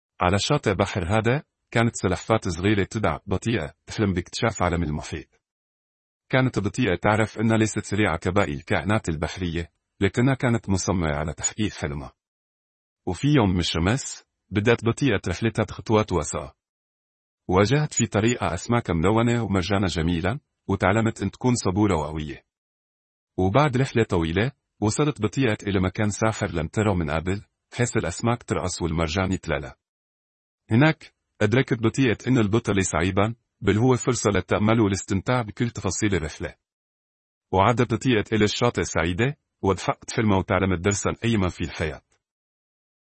أمثلة لنتائج مولد الأصوات المجاني المتقدم VocalAI
٣. تحويل نص مكتوب إلى كلام مسموع بلهجة سورية